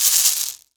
Shaken Shaker 01.wav